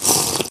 drink.ogg